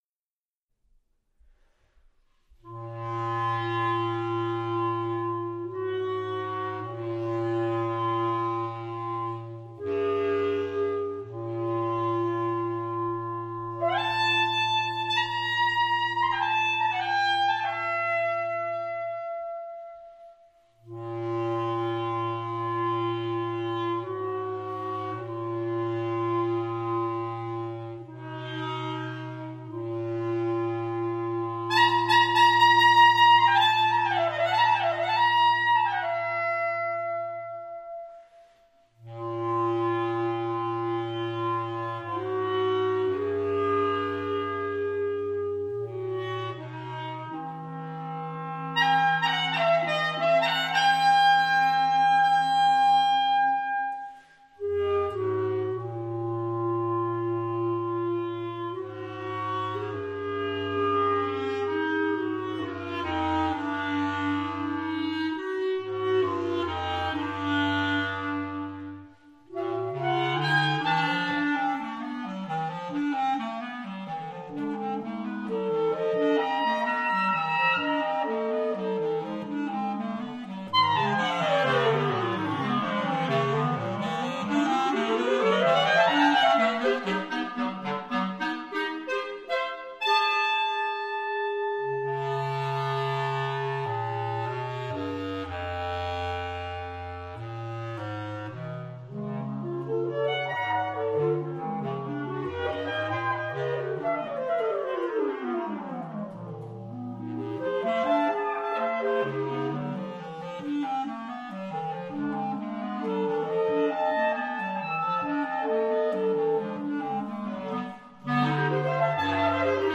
BP clarinets